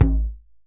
DJEM.HIT11.wav